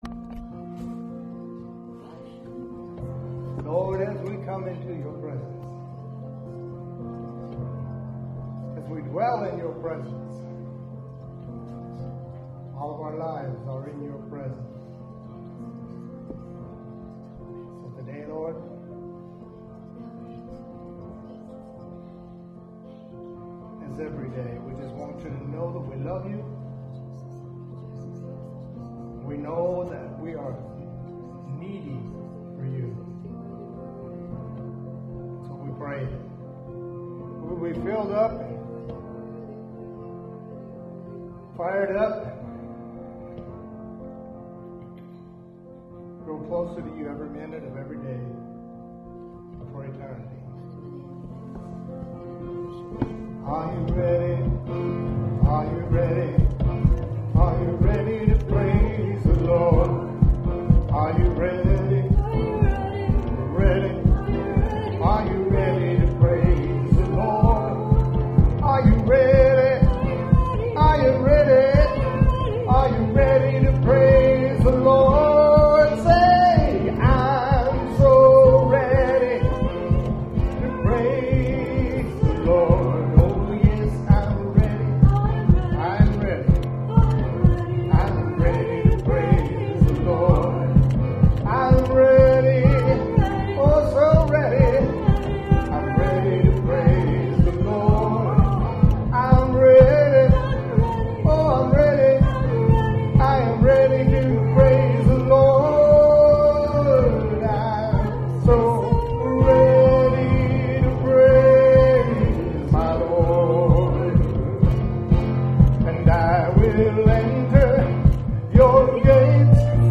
WORSHIP 11 9.mp3